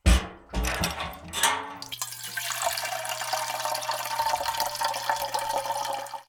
action_refuel_0.ogg